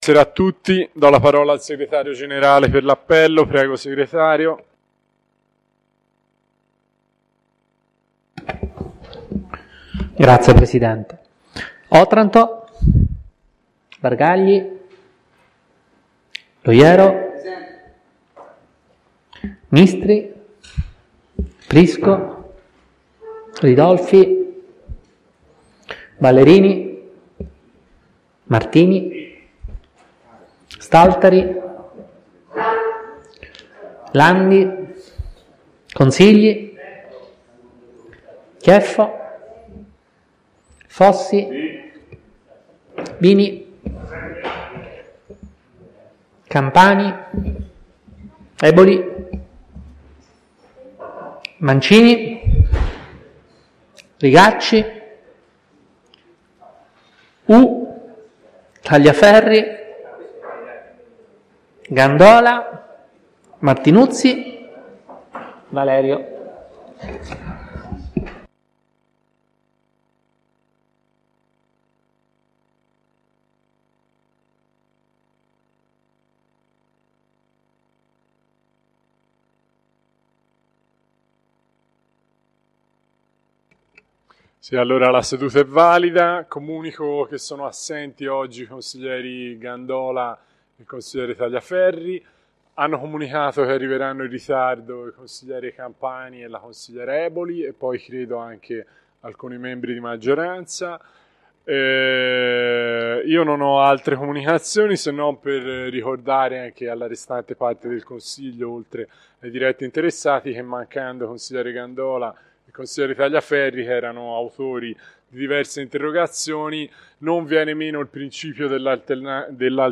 2017-10-19_consiglio-comunale.mp3